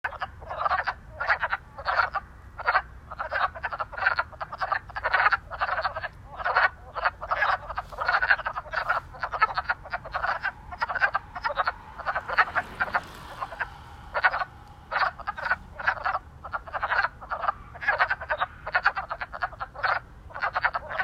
Farther along in a marshy spot below the trail, the peepers were in full tilt—nearly deafening.
First sounds like chickens clucking (sort of)…second sounds like aliens!
Frogs-along-WOD_March-9-2021.m4a